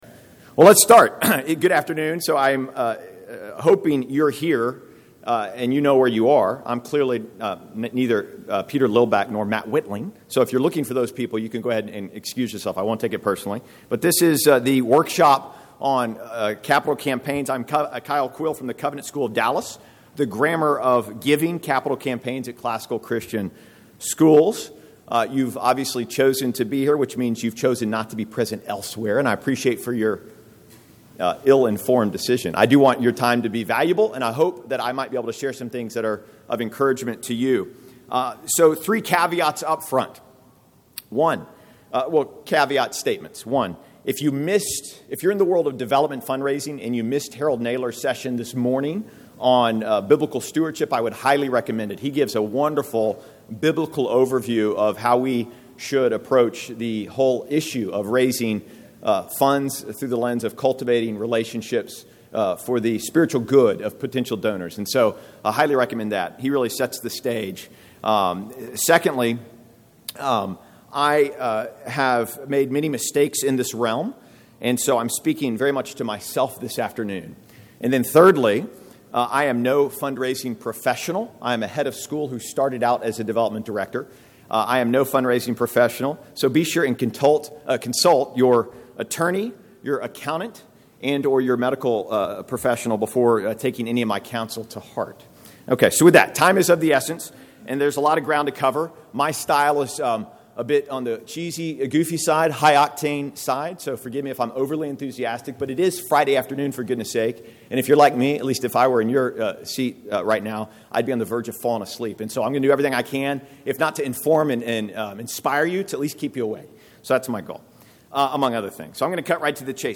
2012 Workshop Talk | 1:00:43 | Fundraising & Development
Speaker Additional Materials The Association of Classical & Christian Schools presents Repairing the Ruins, the ACCS annual conference, copyright ACCS.